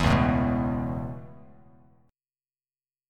Dbm7#5 chord